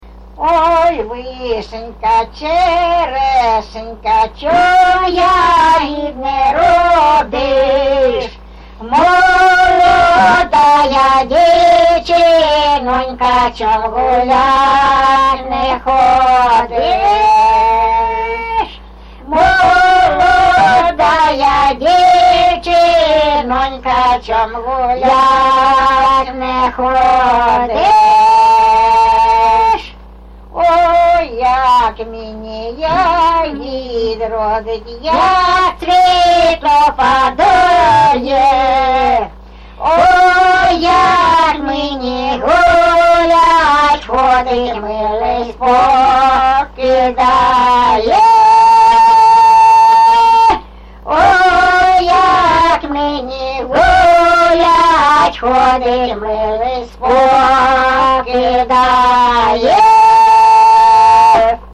ЖанрПісні з особистого та родинного життя
МотивНещаслива доля, Розлука, Журба, туга
Місце записус. Закітне, Краснолиманський (Краматорський) район, Донецька обл., Україна, Слобожанщина